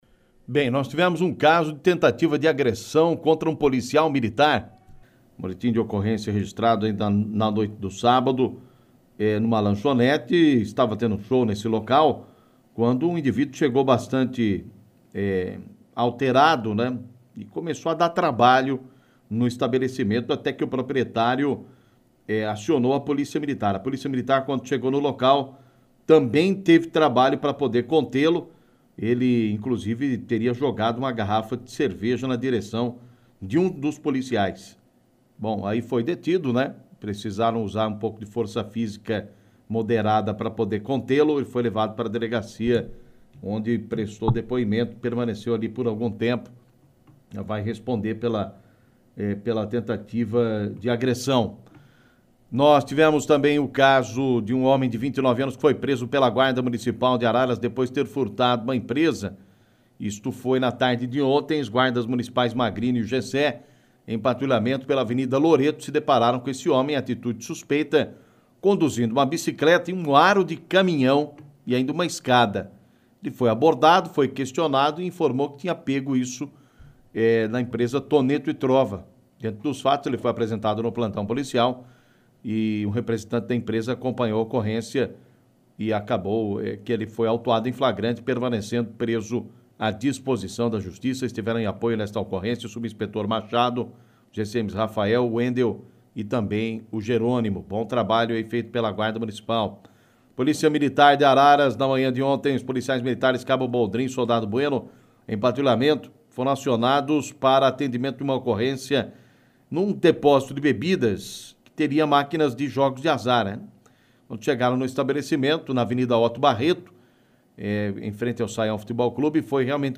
Clique no link abaixo e ouça as principais notícias ocorridas em Araras e região na voz do repórter policial